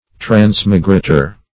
Transmigrator \Trans"mi*gra`tor\, n. One who transmigrates.